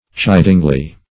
chidingly - definition of chidingly - synonyms, pronunciation, spelling from Free Dictionary Search Result for " chidingly" : The Collaborative International Dictionary of English v.0.48: Chidingly \Chid"ing*ly\, adv. In a chiding or reproving manner.
chidingly.mp3